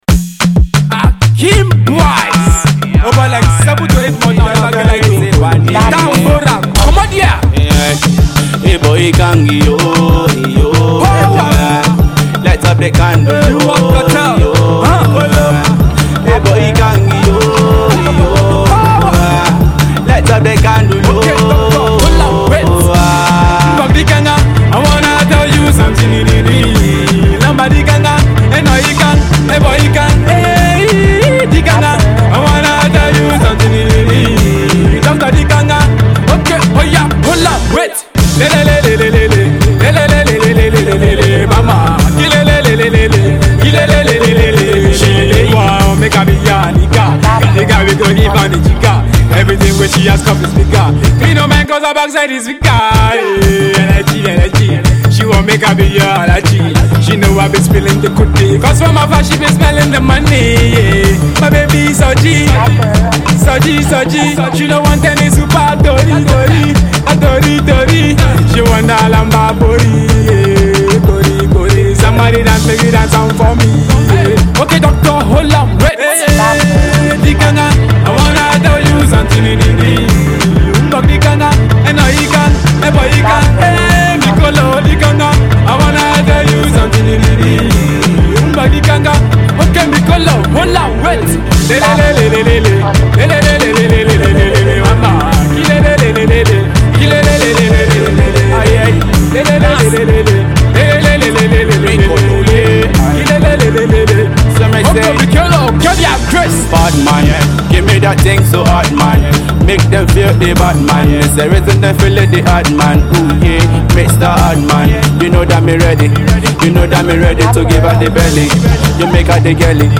Indigenous Pop
A fast growing pop singer rapper